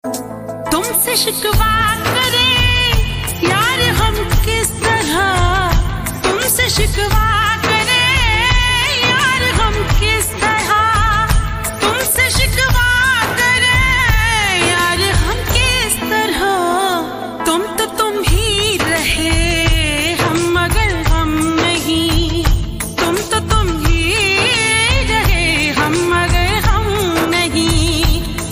Full Song Slow Reverb